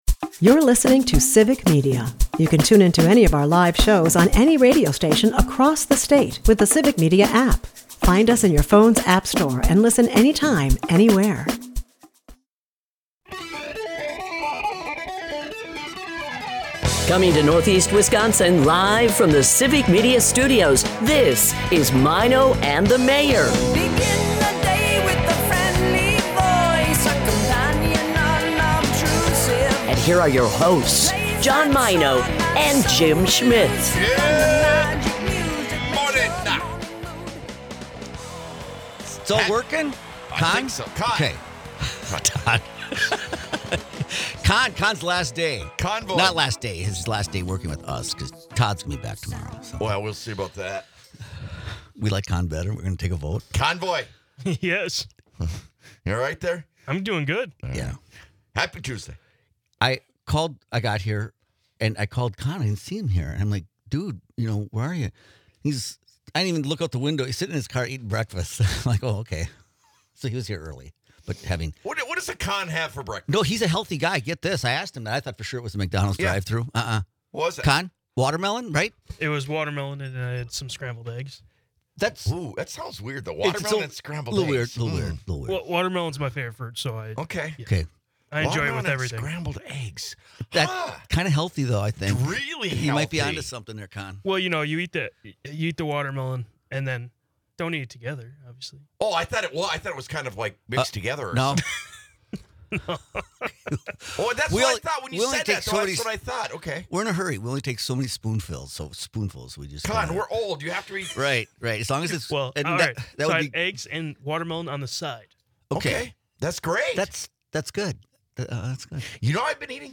She emphasizes the importance of recognizing the signs of stress and utilizing available mental health resources. The episode also touches on the impact of global events on agriculture and the unique pressures of maintaining generational farms. With a mix of light-hearted banter and critical issues, this episode offers insight into the lives of those in agriculture.